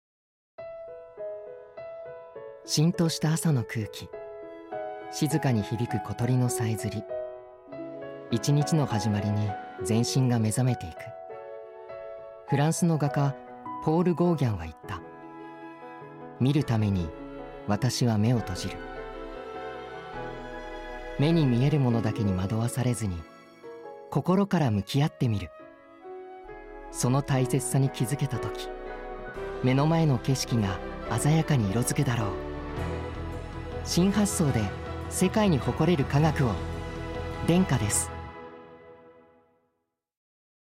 ラジオCM
denka_radiocm_monday.mp3